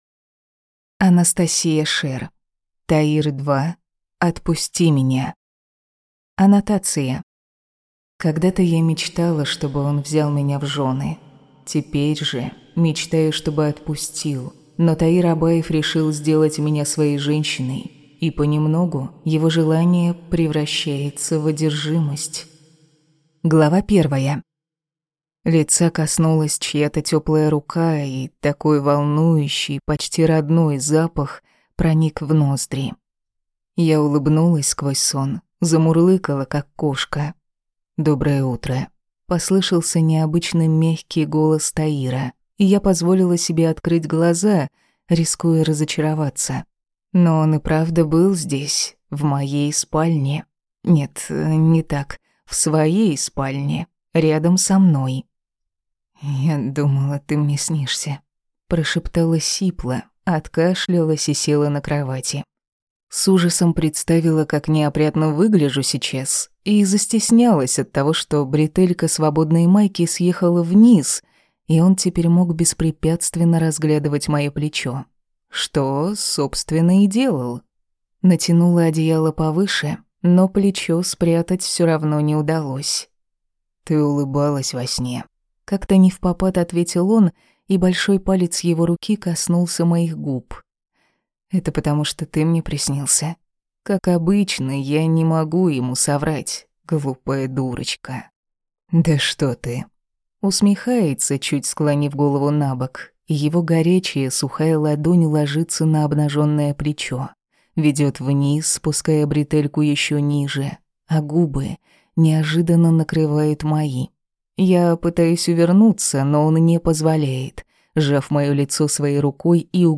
Аудиокнига Таир 2. Отпусти меня | Библиотека аудиокниг